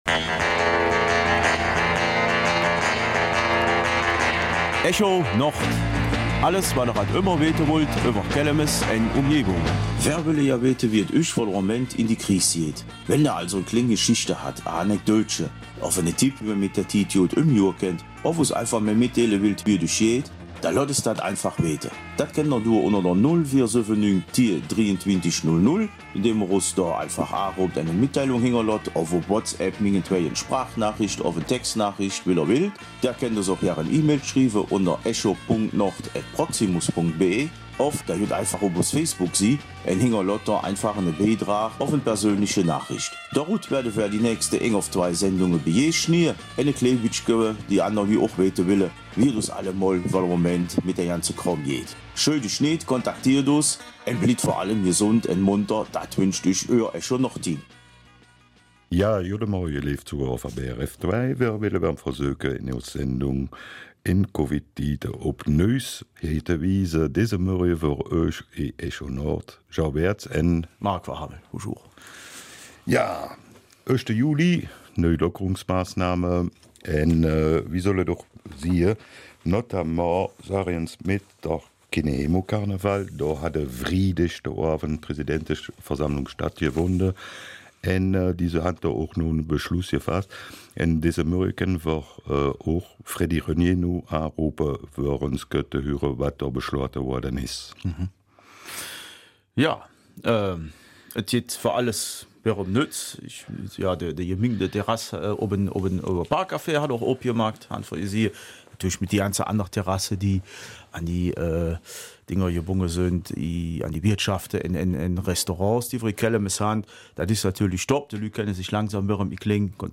Kelmiser Mundart: Echo Nord, wie geht es euch?